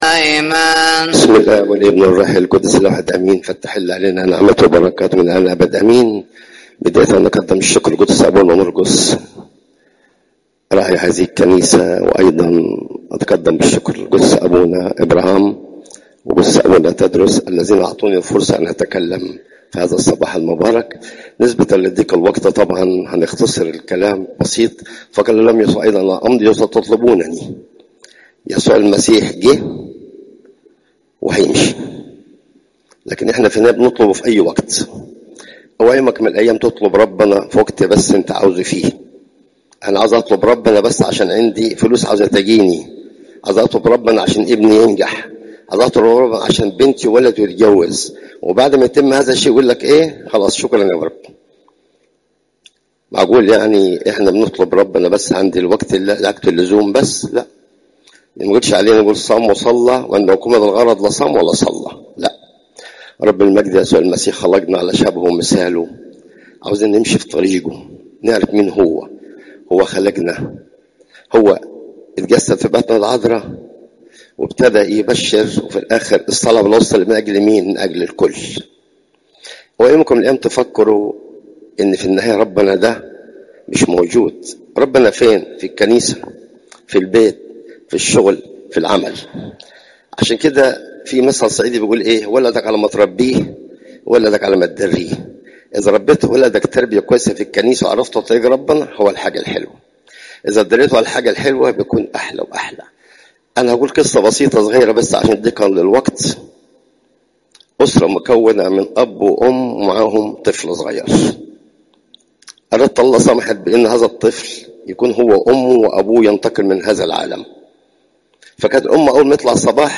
Popup Player تحميل الصوت تحميل الفيديو الخميس، 04 سبتمبر 2025 06:03 عظات قداسات الكنيسة (يو 8 : 21 - 27) شهر مسرى الزيارات: 216